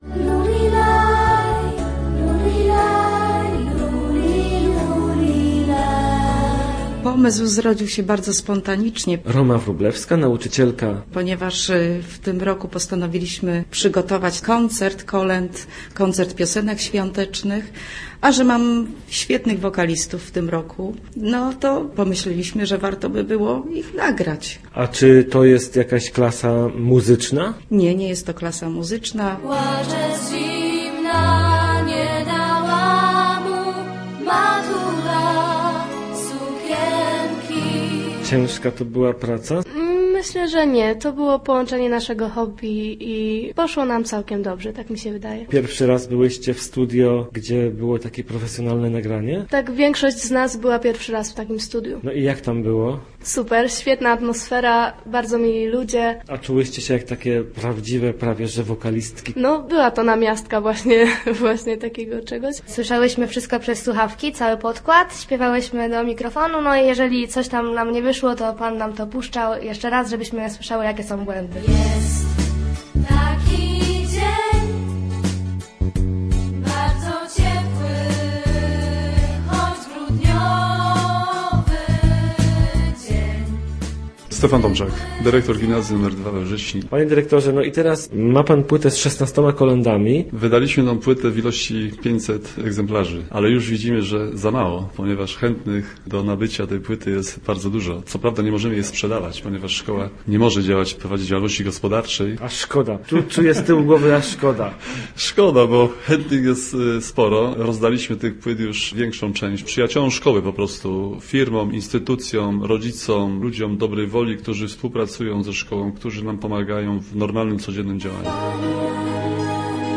Dzieci z Wrześni... zaśpiewały kolędę
Zamiast tradycyjnych kartek ze świątecznym życzeniami - płytę z kolędami nagranymi przez uczniów daje tym razem w prezencie Gimnazjum nr 2 we Wrześni.
h5p3fxo8p3o2lhc_gimnazjalisci_koleda.mp3